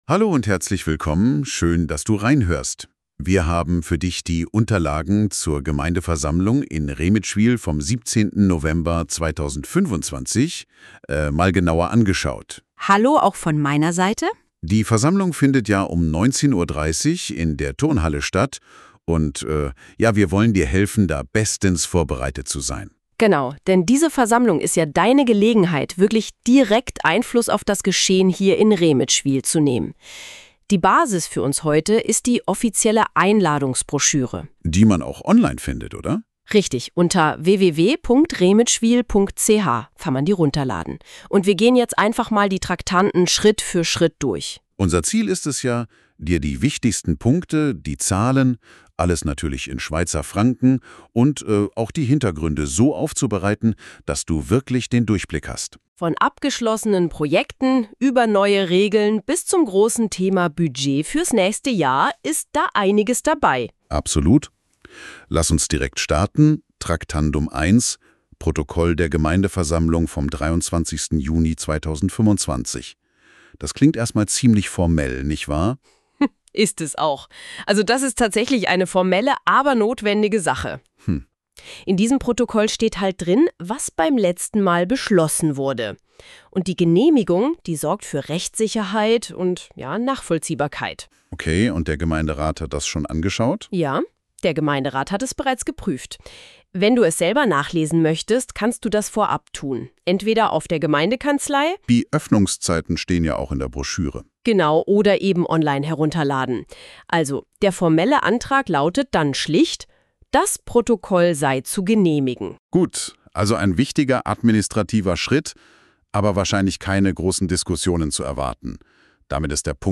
Zusammenfassung der Geschäfte als Podcast (23 Minuten) ; KI-generiert